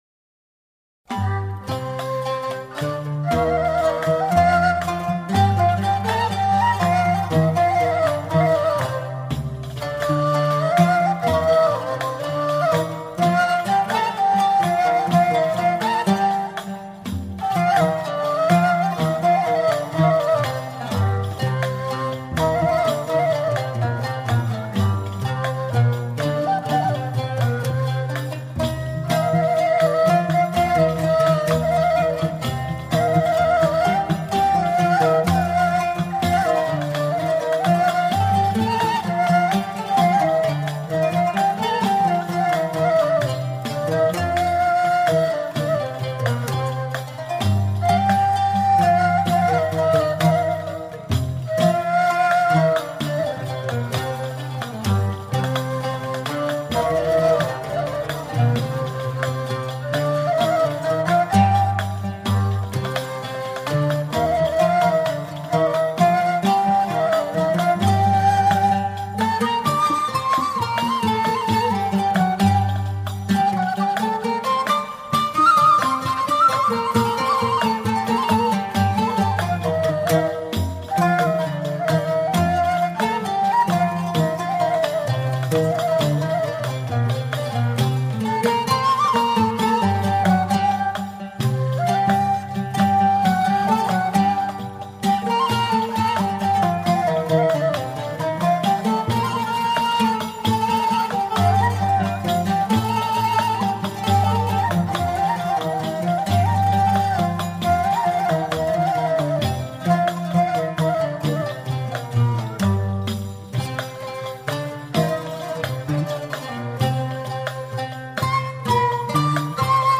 • Instrumental (no vocals)
• Melodically rich, exploring the maqām in a clear, structured way
• Rhythmically steady, often with a long repeating cycle